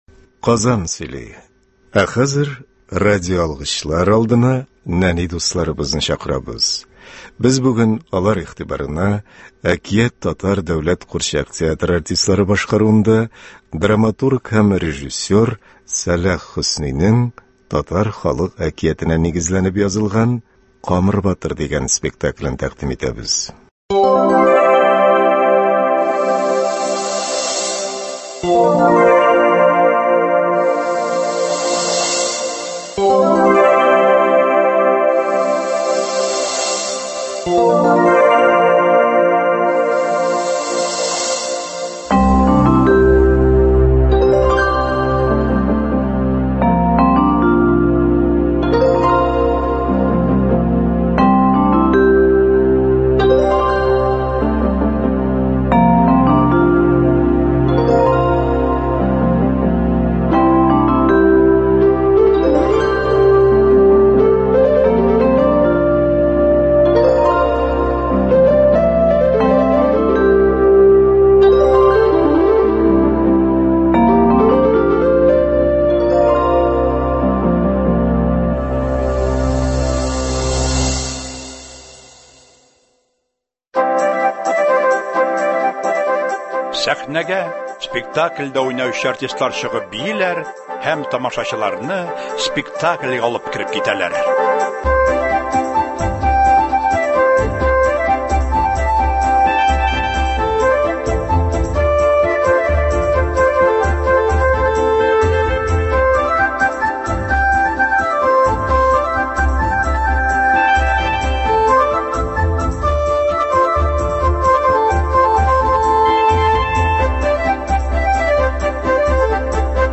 Сәлах Хөсни. “Әкият” Татар дәүләт курчак театры спектакле.
Игътибарыгызга радиобыз фондыннан язучы Салих Хөснинең “Камыр батыр” әкияте буенча эшләнгән радиоспектакль тәкъдим итәбез.